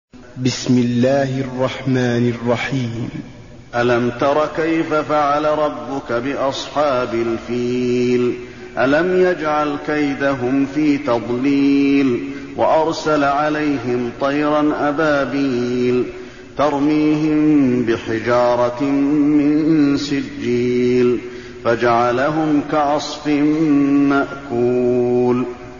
المكان: المسجد النبوي الفيل